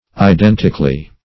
Identically \I*den"tic*al*ly\, adv.